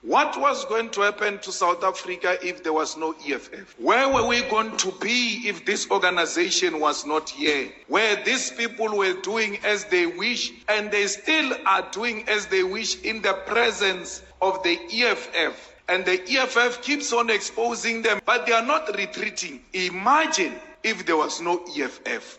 Die EFF-leier Julius Malema sê die ANC sal nie die 2024 nasionale en provinsiale verkiesings oorleef nie. Malema het die Mpumalanga Ground Forces Forum in Mbombela toegespreek.